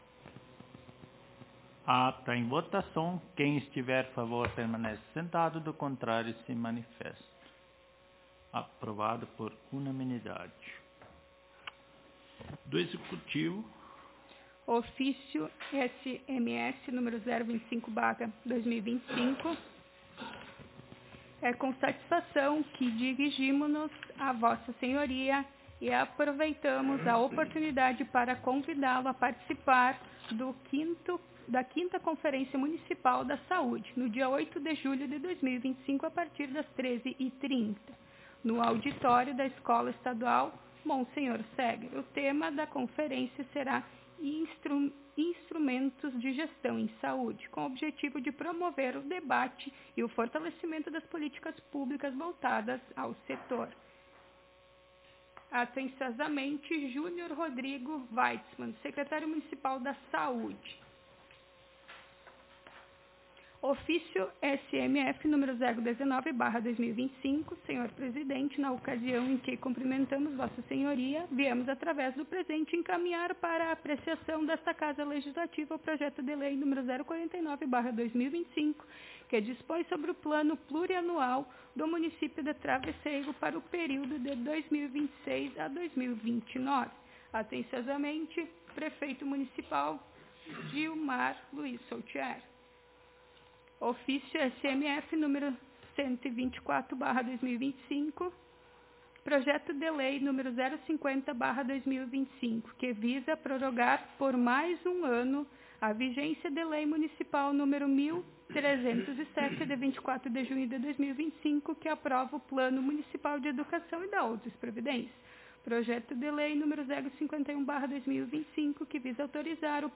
Aos 07 (sete) dias do mês de julho do ano de 2025 (dois mil e vinte e cinco), na Sala de Sessões da Câmara Municipal de Vereadores de Travesseiro/RS, realizou-se a Décima Terceira Sessão Ordinária da Legislatura 2025-2028.